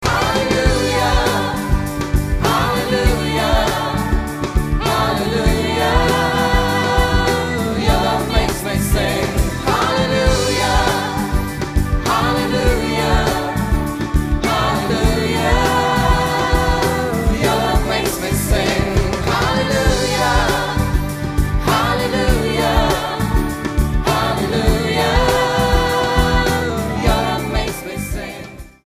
STYLE: Pop
This is a studio produced album